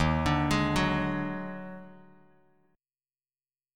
D#mM11 chord